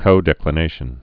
(kōdĕk-lə-nāshən)